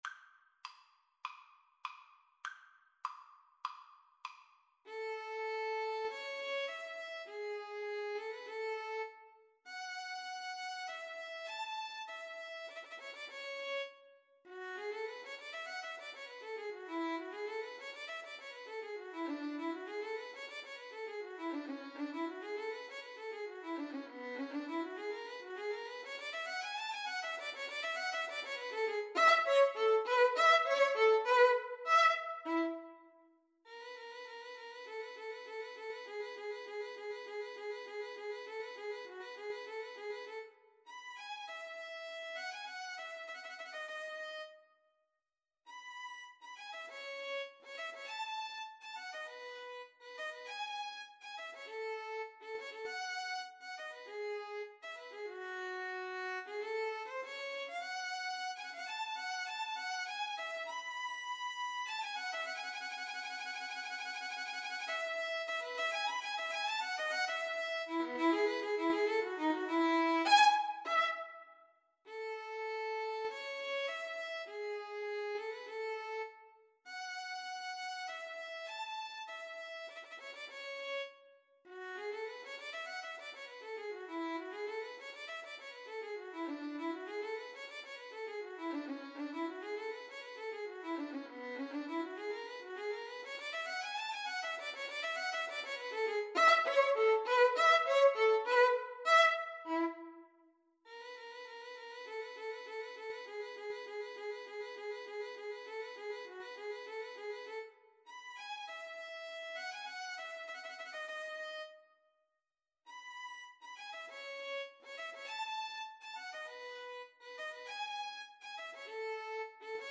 Free Sheet music for Violin Duet
Violin 1Violin 2
A major (Sounding Pitch) (View more A major Music for Violin Duet )
4/4 (View more 4/4 Music)
Classical (View more Classical Violin Duet Music)